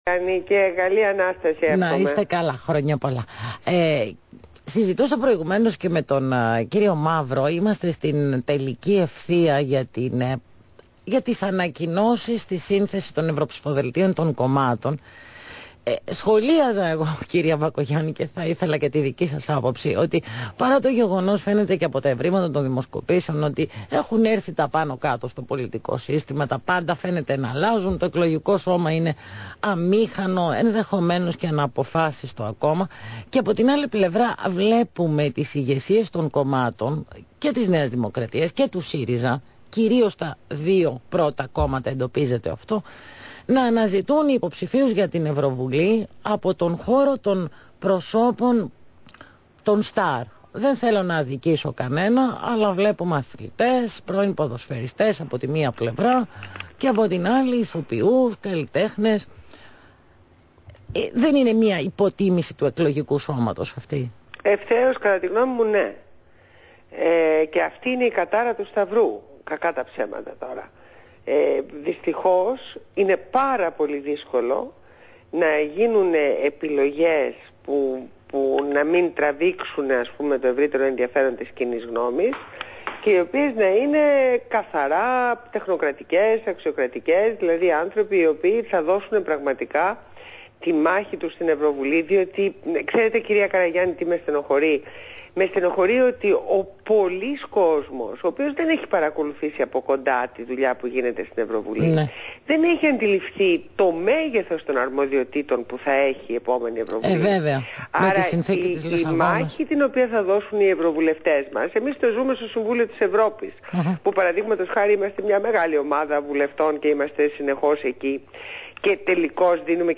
Συνέντευξη στο ραδιόφωνο του Αθήνα 9,84